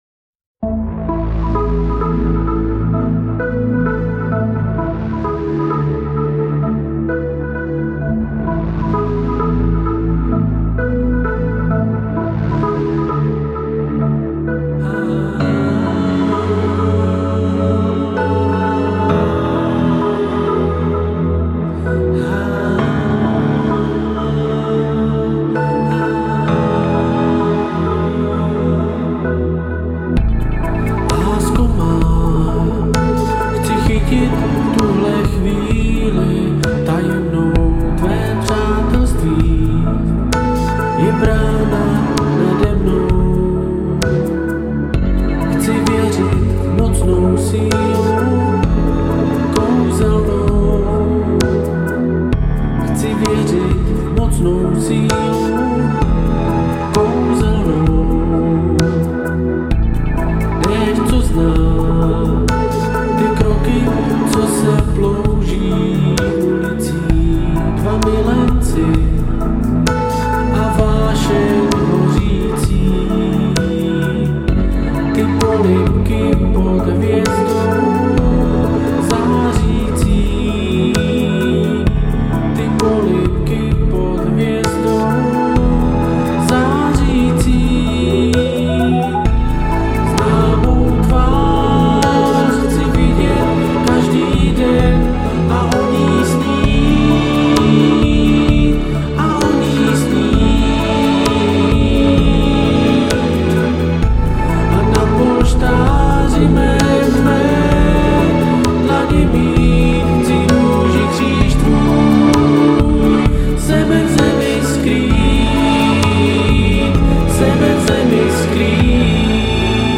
Žánr: Electro/Dance
místy zase spíš zklidňující.